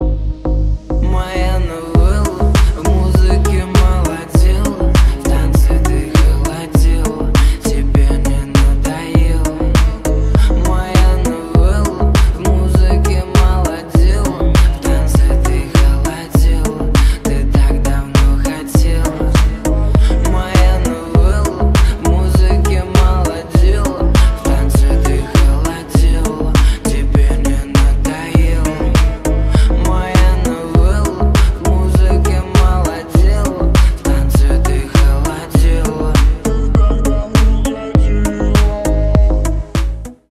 Ремикс # Поп Музыка
тихие